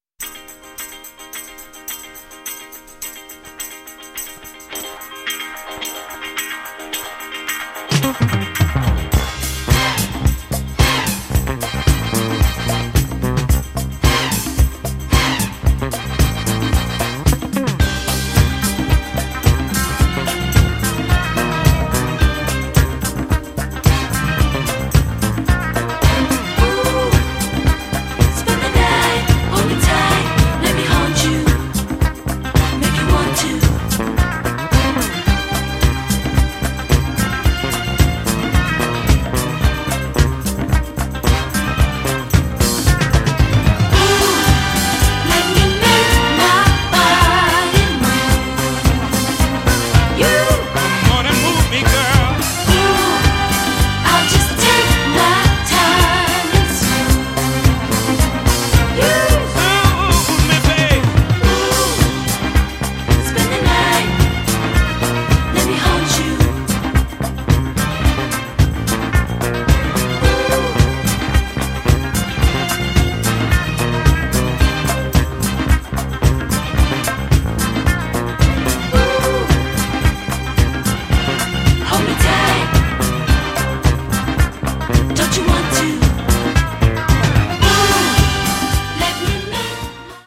4 monstruous tracks straight from the basement